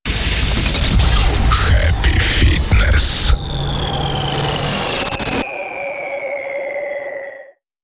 Примеры звуковых логотипов